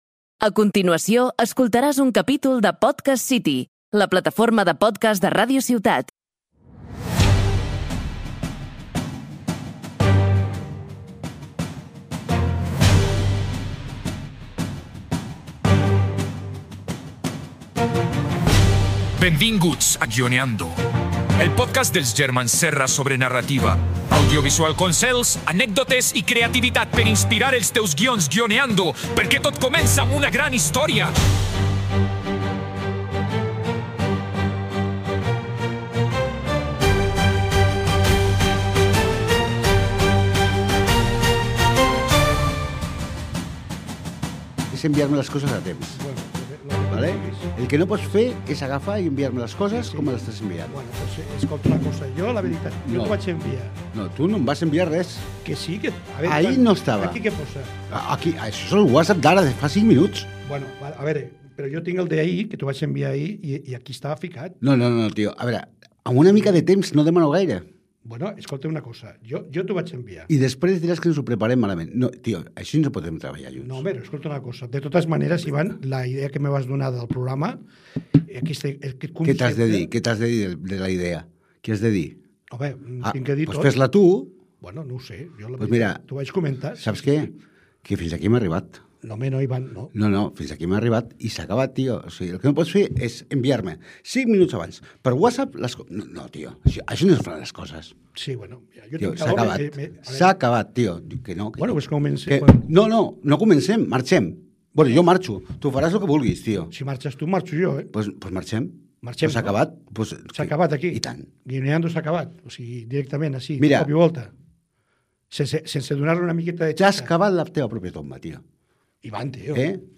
Comença l’episodi amb una discussió que sembla un cliffhanger en directe.